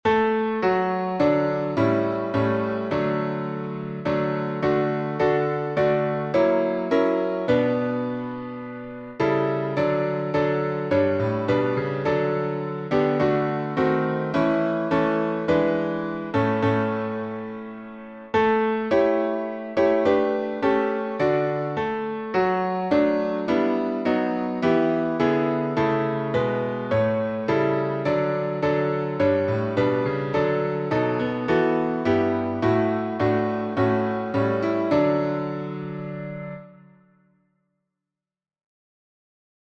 Words by Fanny J. Crosby (1820-1915) Hart Pease Danks (1834-1903) Key signature: D major (2 sharps